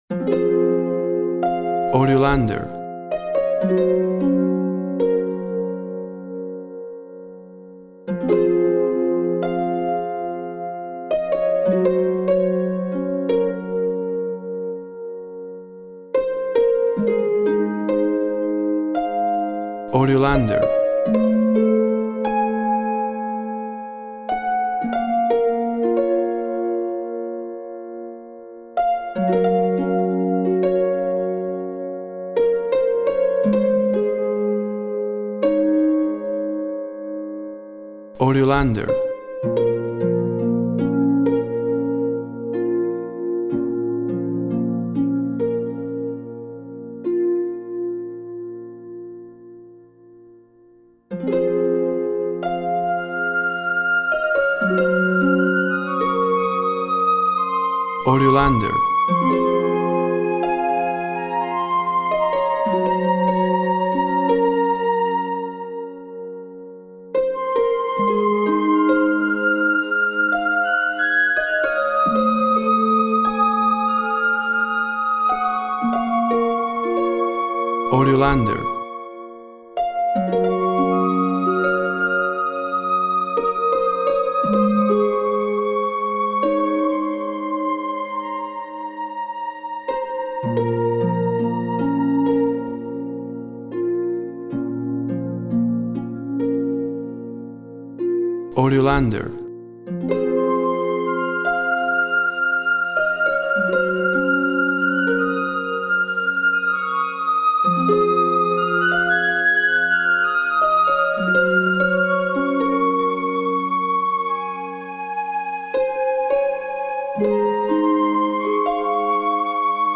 Harp and flute sing a mournful duet.
Tempo (BPM) 56